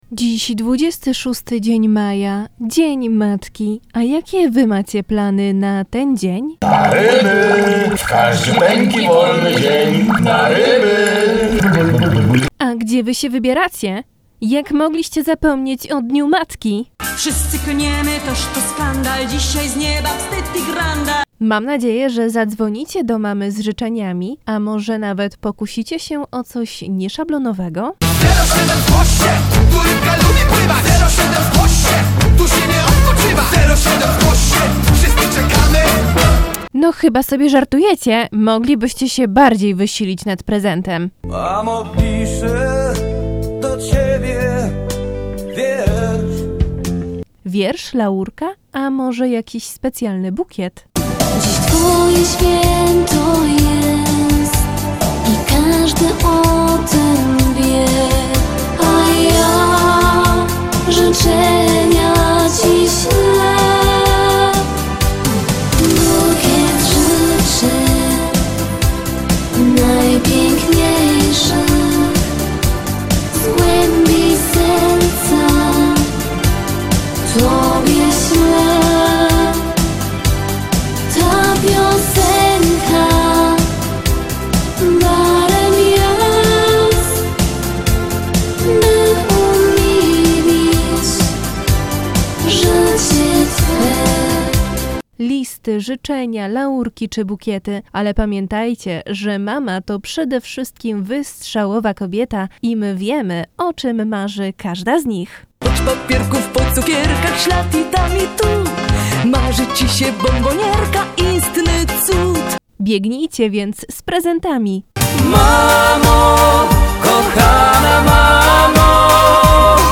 26.05 DZIEN MATKI AUDYCJA MP3 OK (1)Pobierz